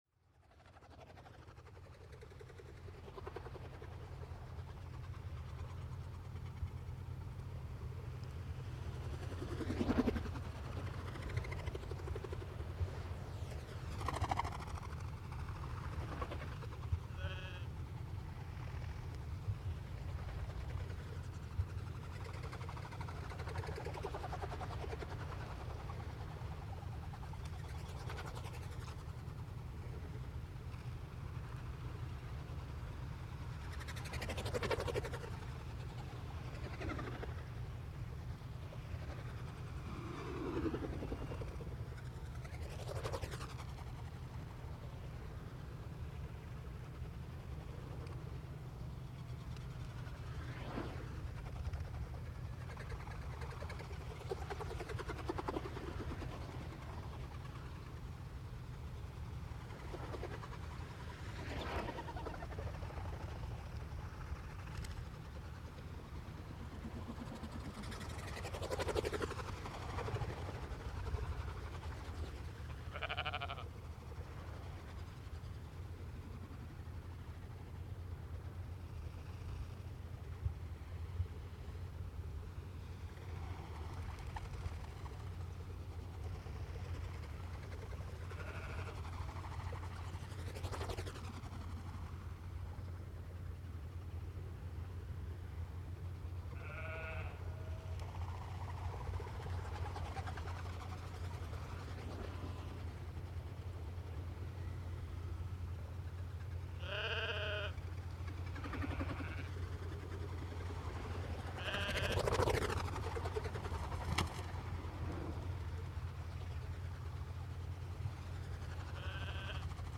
AB40 recording, Atlantic Puffin, Black Guillemot, Black-legged Kittiwake, Common Eider, Common Murre, Common Raven